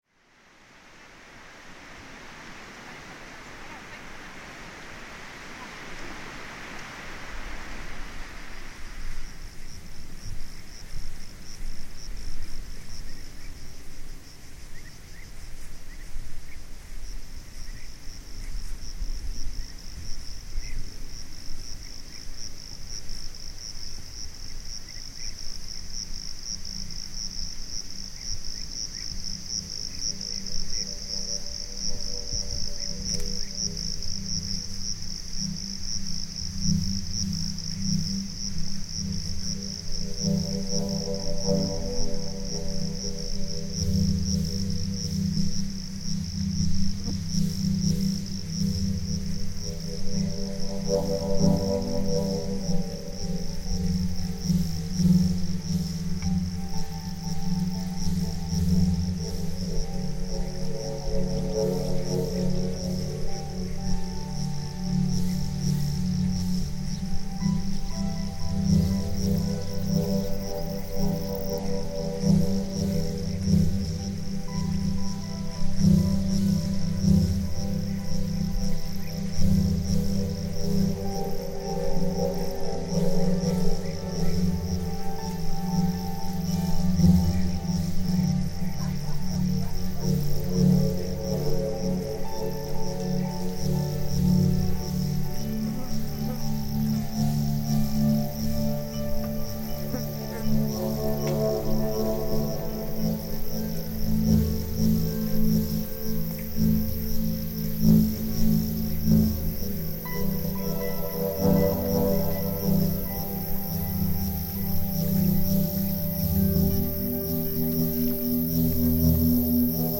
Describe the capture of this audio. Rosolina Mare beach soundscape reimagined